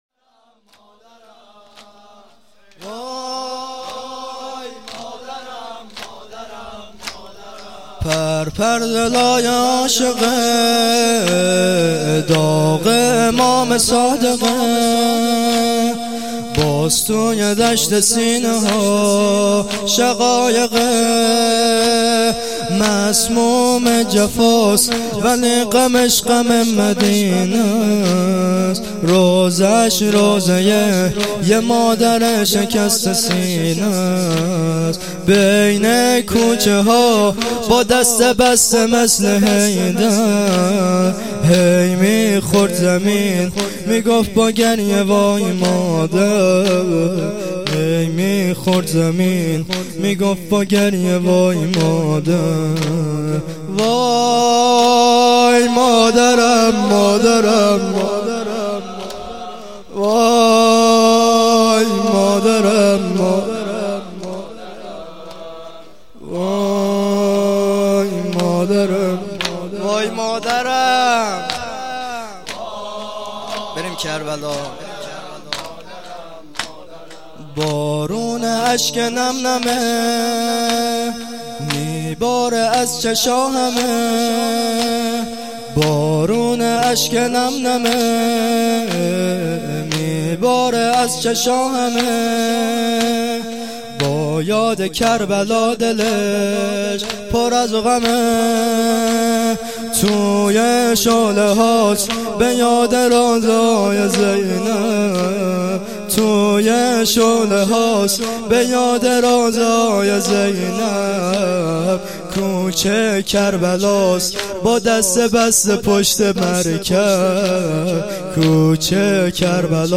شهادت امام صادق علیه السلام ، 97/4/14